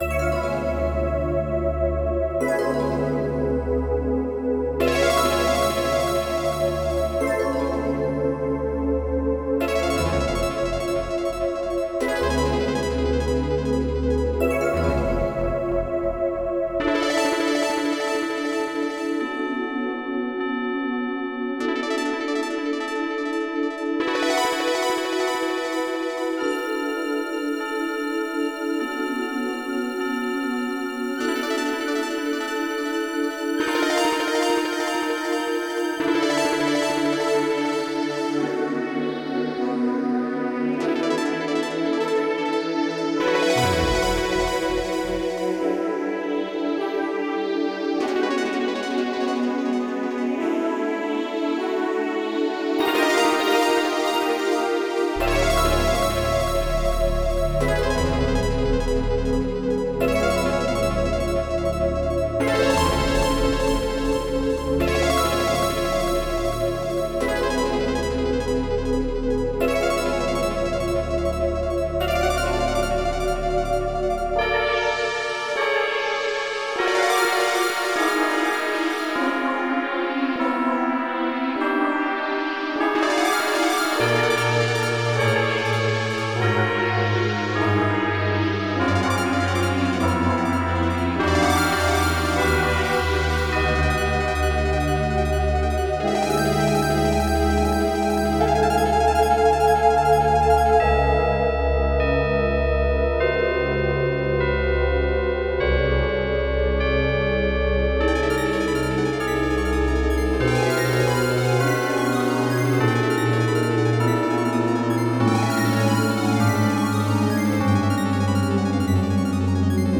Extended MIDI